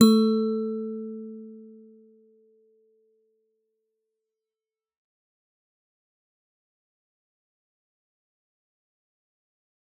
G_Musicbox-A3-f.wav